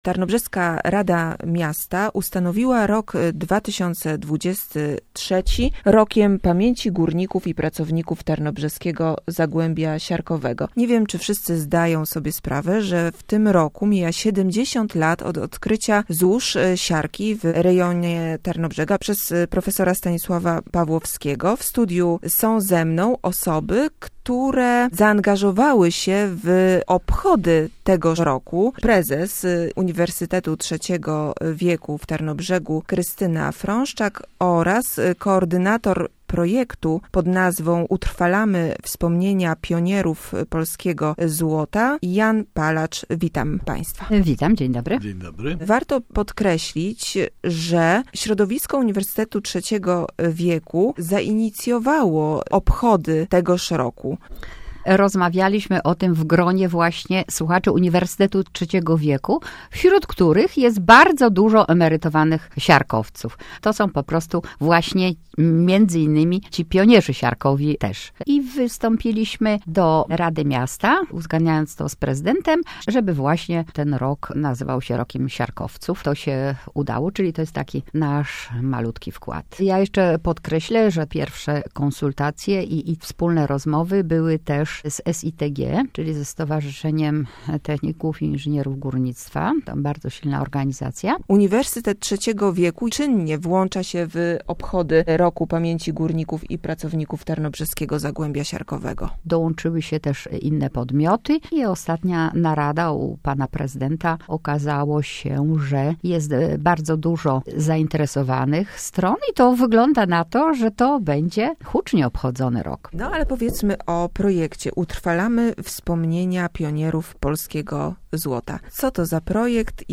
W Tarnobrzegu rok 2023 został ustanowiony rokiem 'Pamięci Górników i Pracowników Tarnobrzeskiego Zagłębia Siarkowego’. Rozmowa